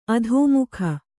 ♪ adhōmukha